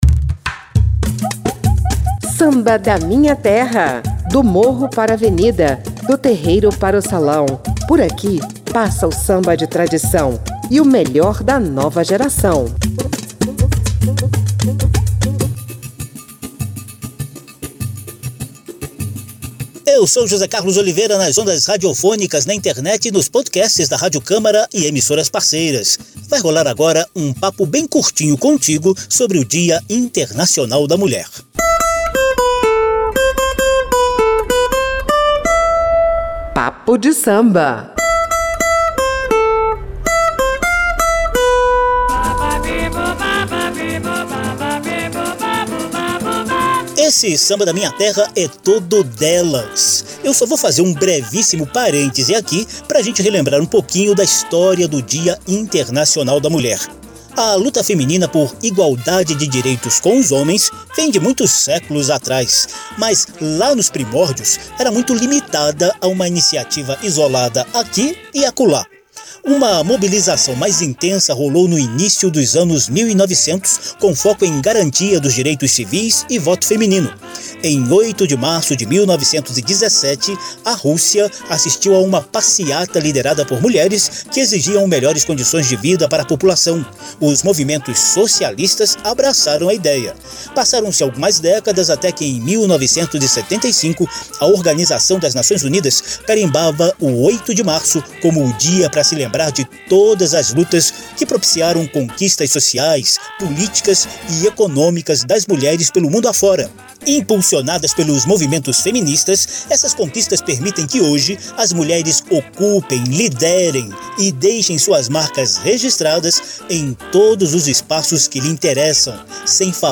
Inclusive no samba, onde elas imprimem olhar, vivência e sensibilidade diferenciadas aos cantos, letras e batuques do ritmo mais popular do país. Por esses e outros motivos, essa edição de Samba da Minha Terra é totalmente DELAS: compositoras, cantoras, cronistas e poetisas que atuam na criação, na interpretação e na reinvenção da cultura brasileira.